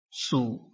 臺灣客語拼音學習網-客語聽讀拼-海陸腔-單韻母